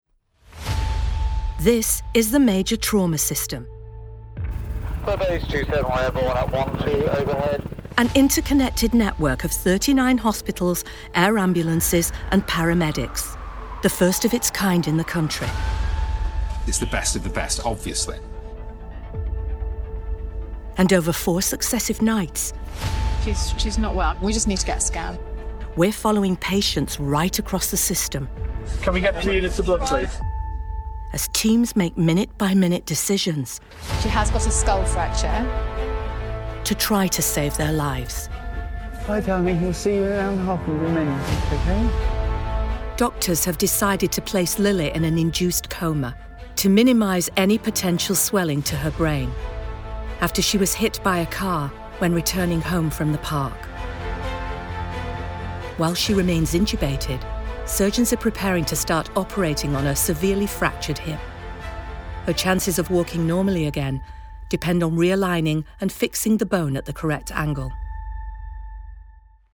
40/50's Northern,
Friendly/Sassy/Warm